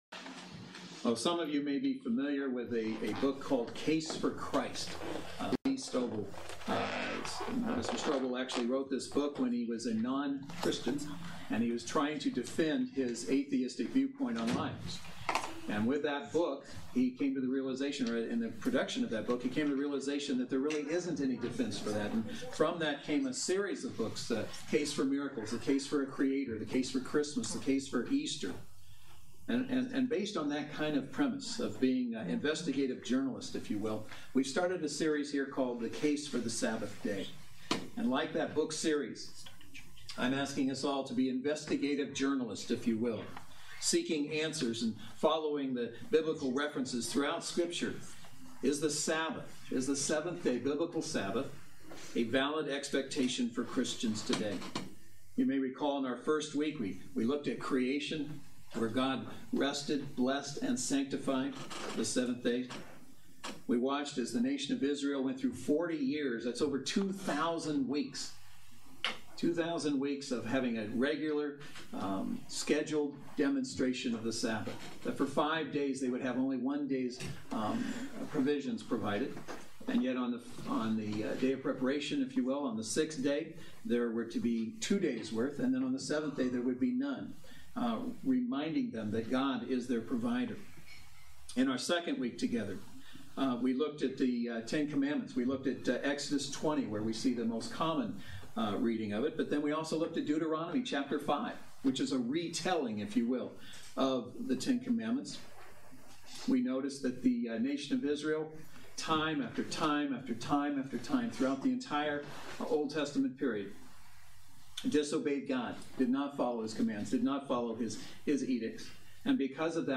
Series: The Case for the Sabbath Day Service Type: Saturday Worship Service